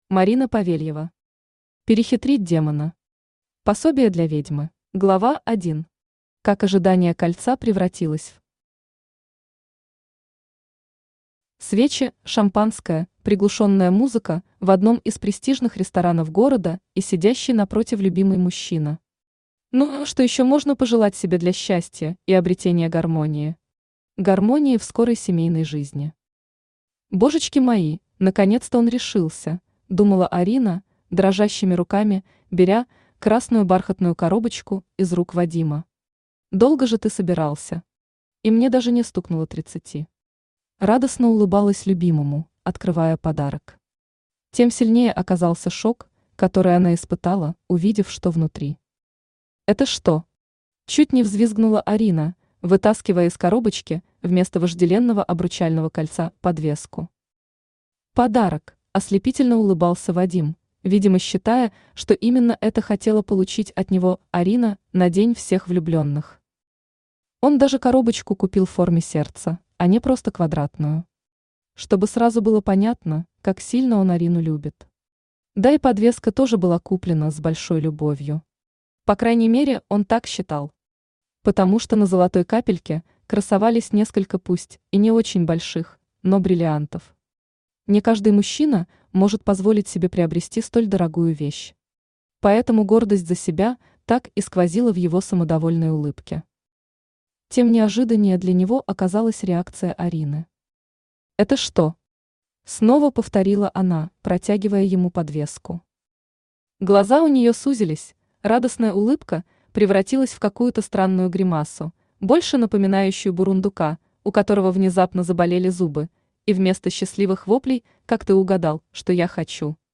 Аудиокнига Перехитрить демона. Пособие для ведьмы | Библиотека аудиокниг
Пособие для ведьмы Автор Марина Павельева Читает аудиокнигу Авточтец ЛитРес.